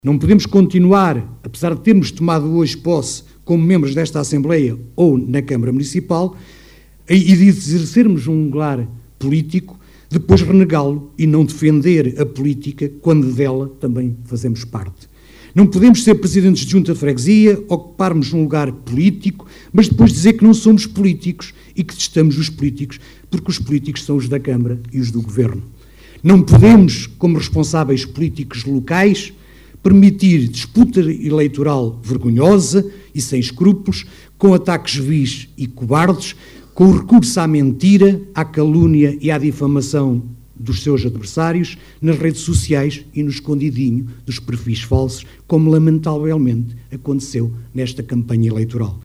A cerimónia decorreu ao final da tarde, no Centro de Educação e Recreio (CER) de Vagos.